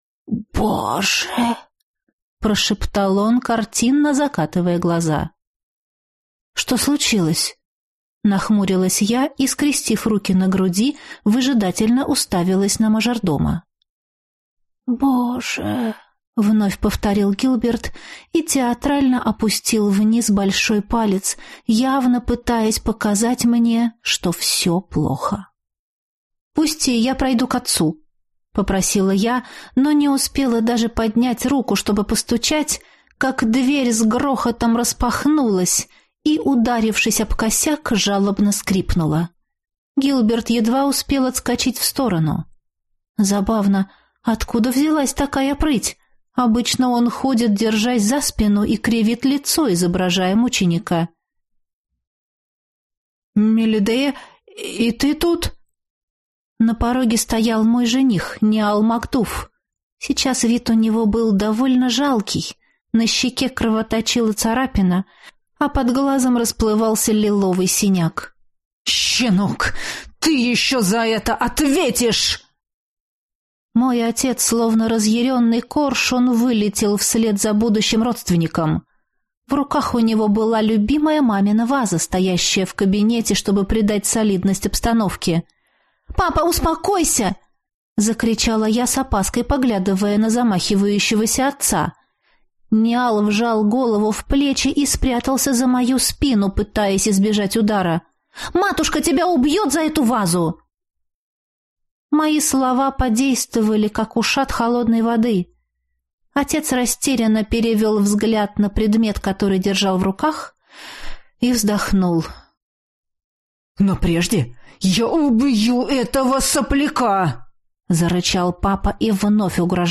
Аудиокнига Возлюбленный на одну ночь | Библиотека аудиокниг